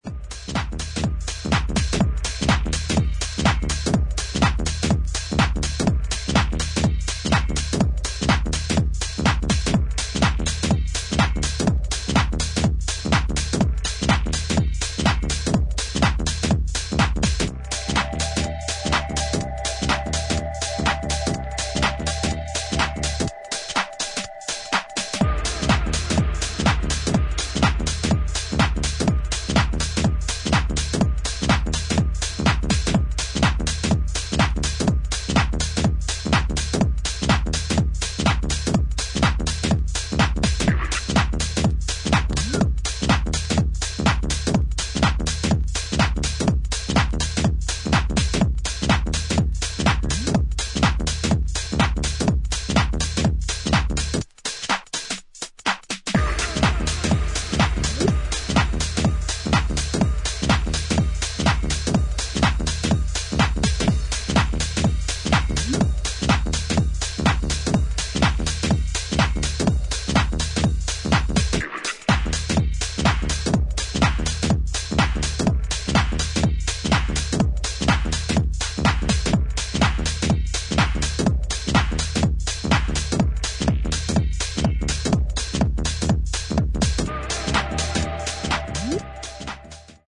』で構成された、クールなテックハウス4曲を収録。